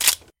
Gun_Prep.mp3